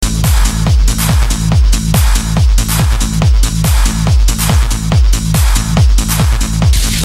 very hard to identify this trance/hard trance tune
Very hard to identify this track because i have only 7 second from the begining (no melody only beat) and then live broadcasting stoped.